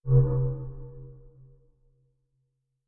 Special Click 34.wav